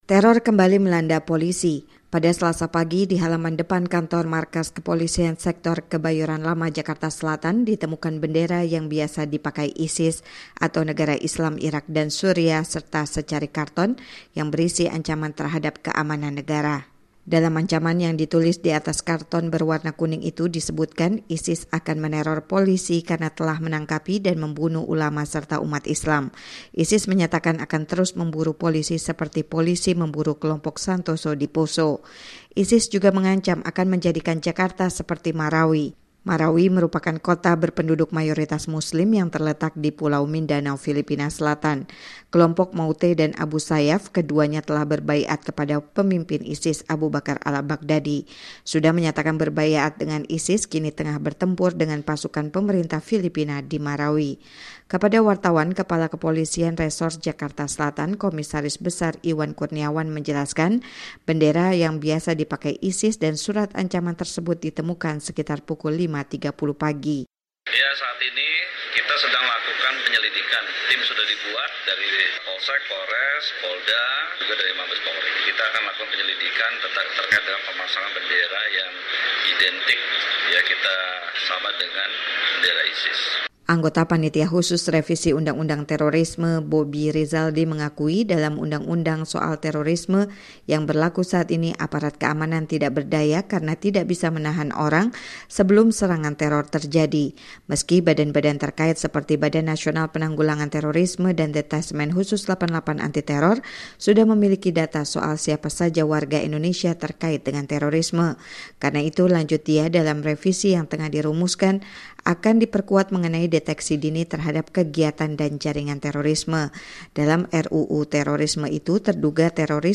reporter VOA melaporkannya dari Jakarta.